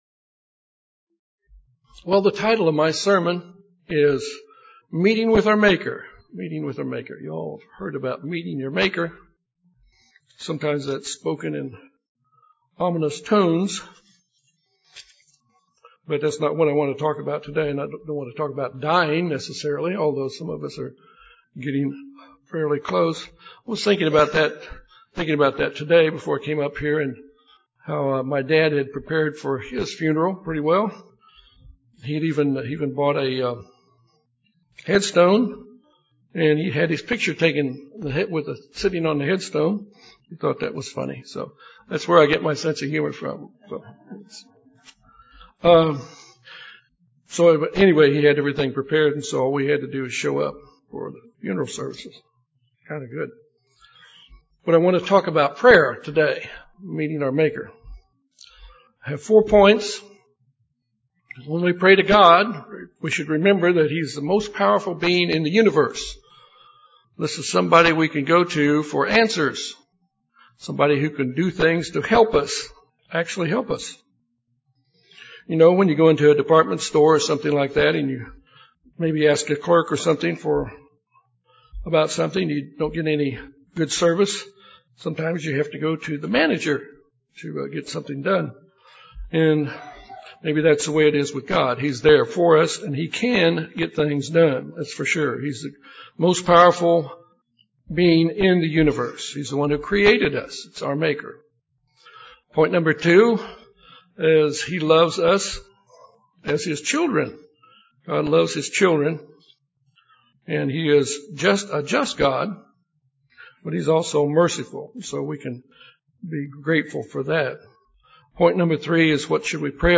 This sermon is about a fundamental subject of our Christian life - prayer.